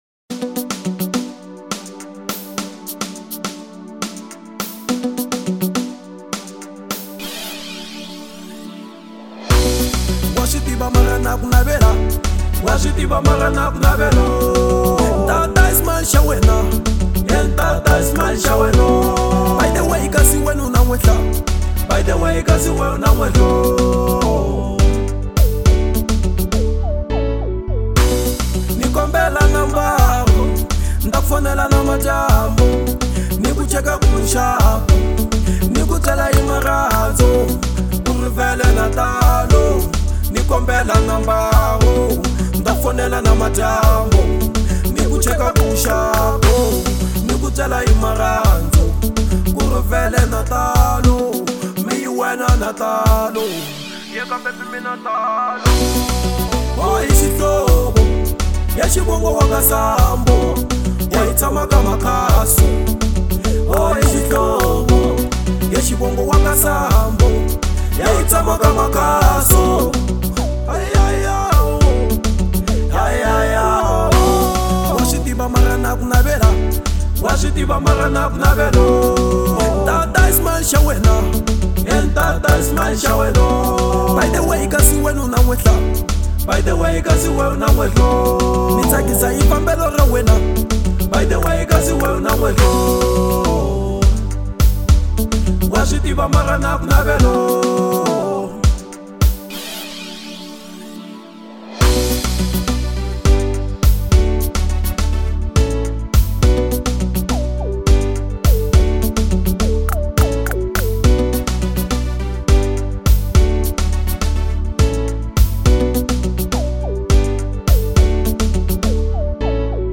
03:16 Genre : RnB Size